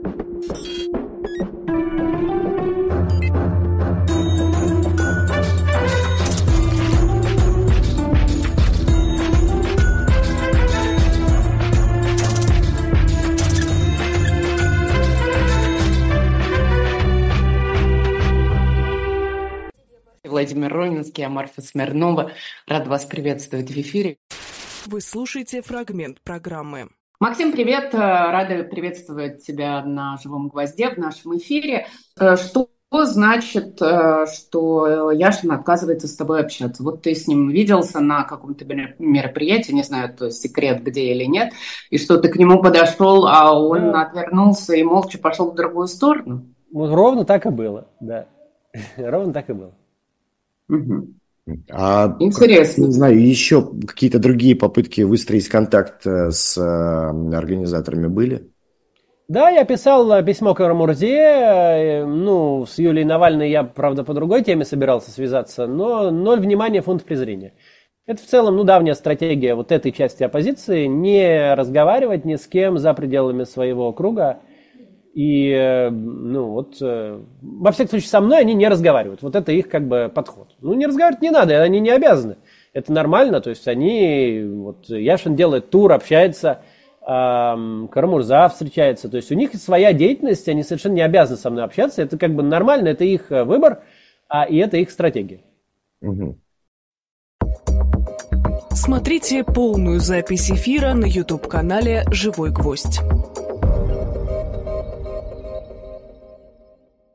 Фрагмент эфира от 05.11